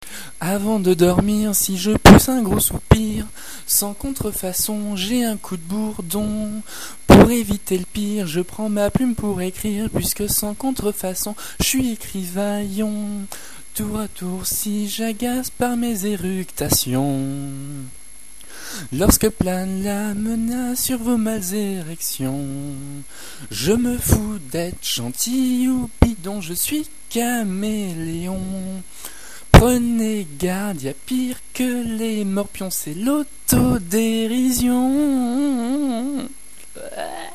Solos
parodie